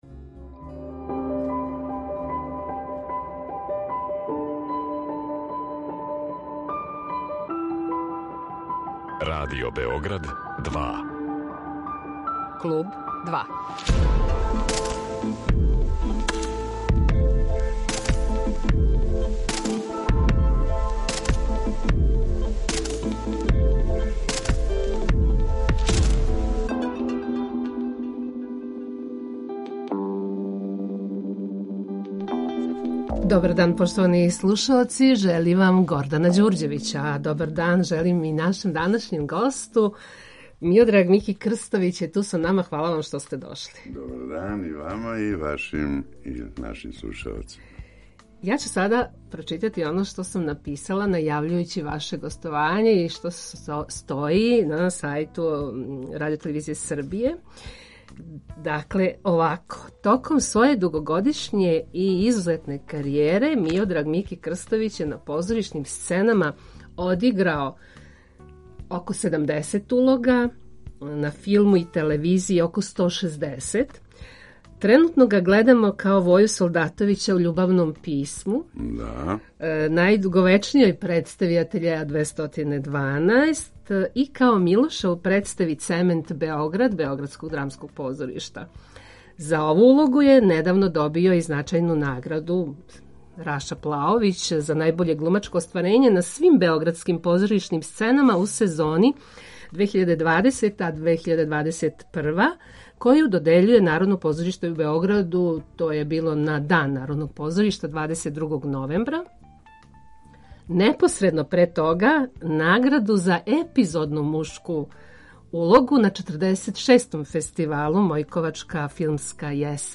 Гост Клуба 2 је један од наших најзначајнијих глумаца - Миодраг Мики Крстовић.